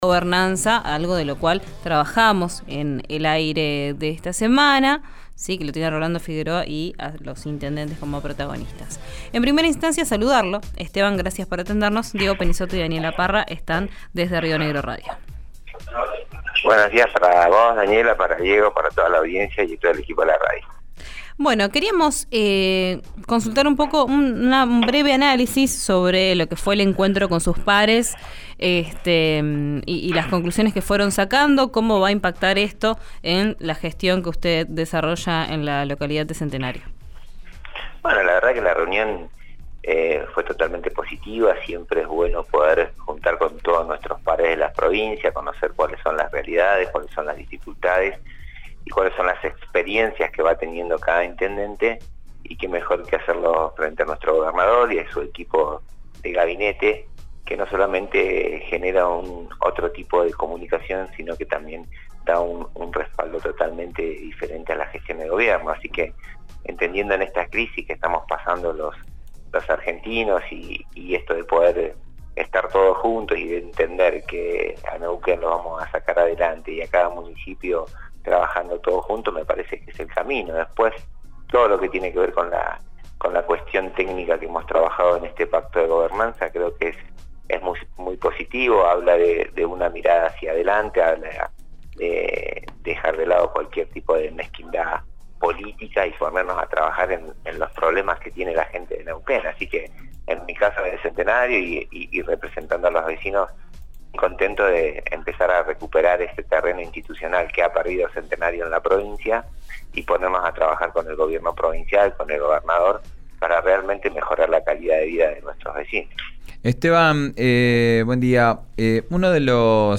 Escuchá al intendente Esteban Cimolai en RÍO NEGRO RADIO: